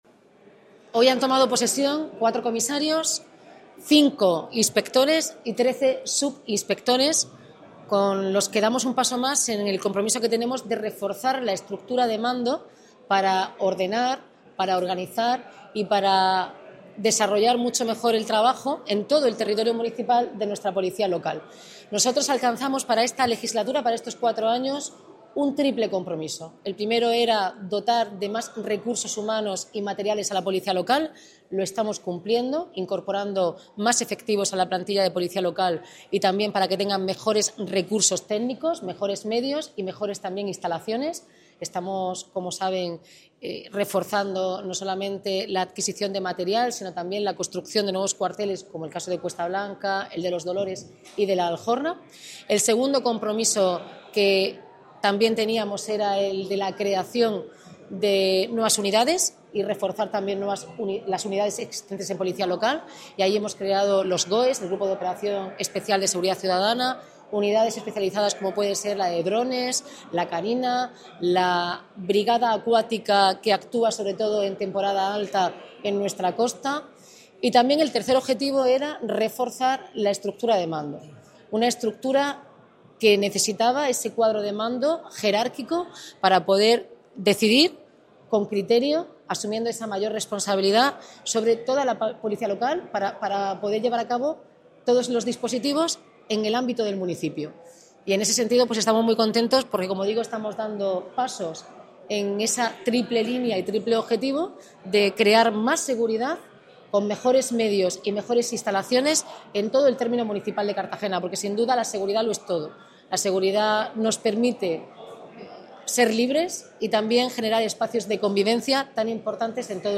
Enlace a Declaraciones de la alcaldesa, Noelia Arroyo, en la toma de posesión de mandos de Policía Local